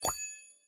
main_upgrade_succ.mp3